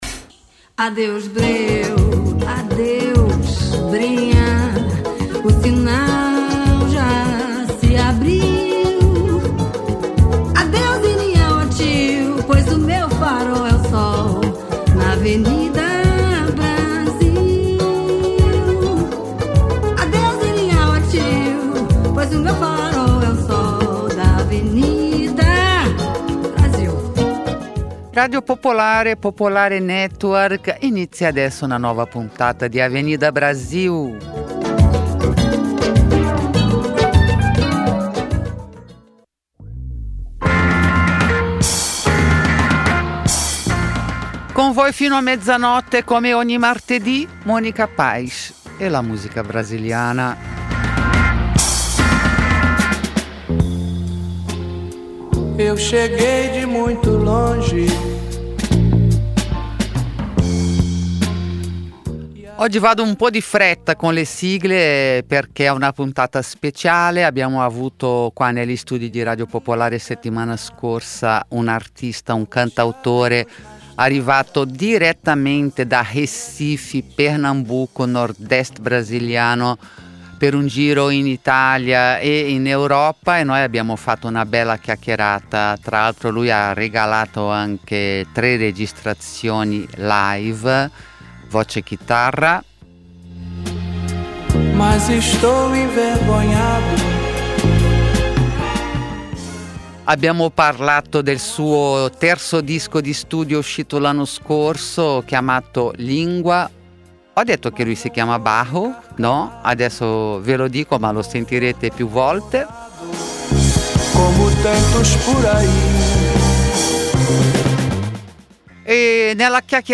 voce e chitarra live in studio